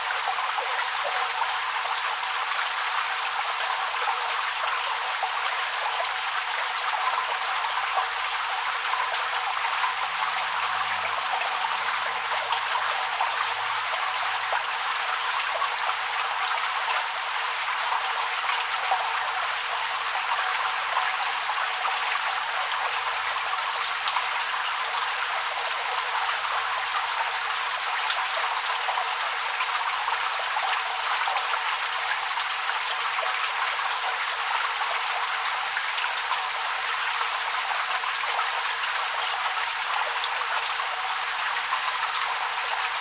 Звуки душа
На этой странице собраны разнообразные звуки душа: от мягкого потока воды до интенсивного массажного режима.